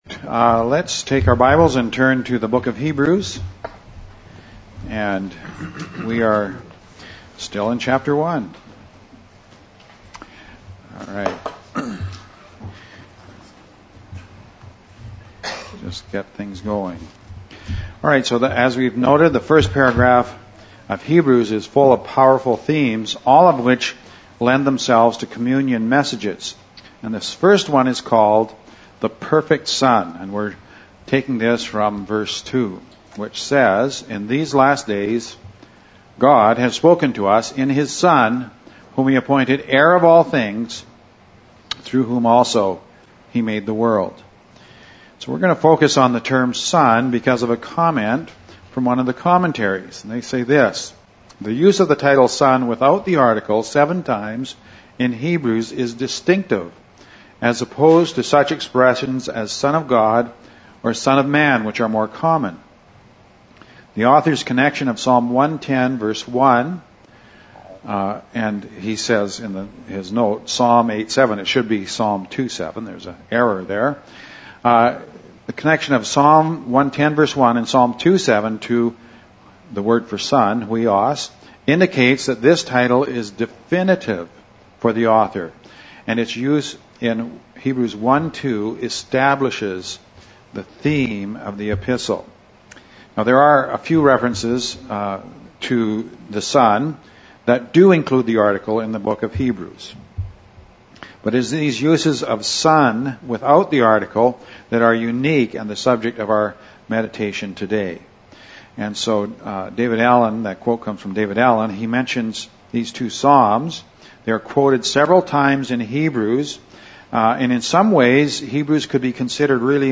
Heb 1.2 – For our communion service, we look at the use of the word “Son” in Hebrews (without the article) as a particular way of highlighting the unique person of our Saviour.